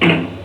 bumperding1.wav